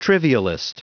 Prononciation du mot trivialist en anglais (fichier audio)
Prononciation du mot : trivialist